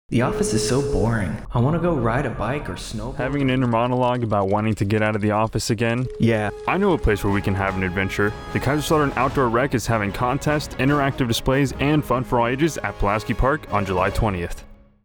Radio Spot - Kaiserslautern Outdoor Recreation